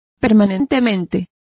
Also find out how permanentemente is pronounced correctly.